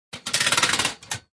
Descarga de Sonidos mp3 Gratis: tabla planchar.
descargar sonido mp3 tabla planchar